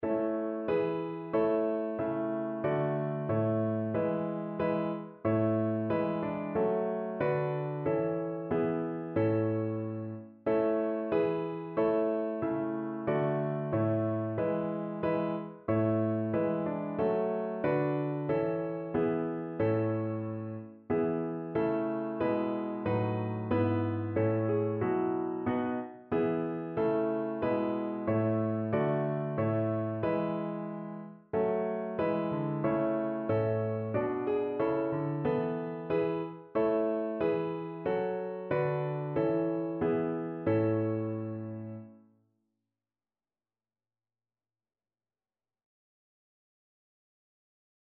Notensatz 1 (4 Stimmen gemischt)
• gemischter Chor [MP3] 749 KB Download